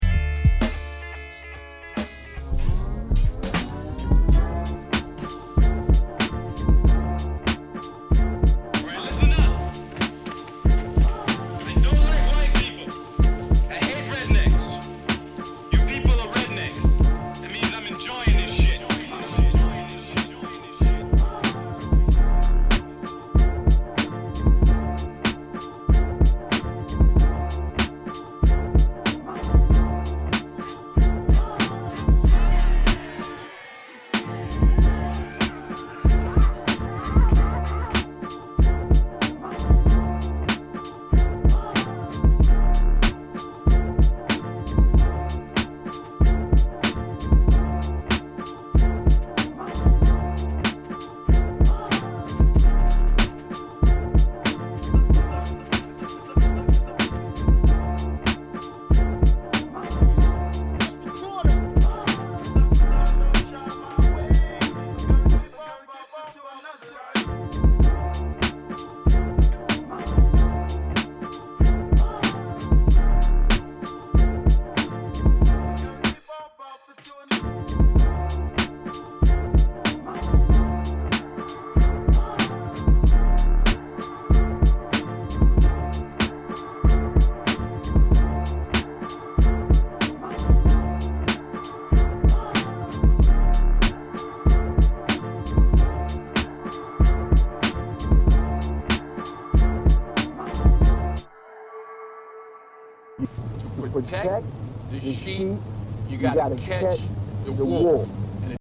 Case in point, check this sample beat.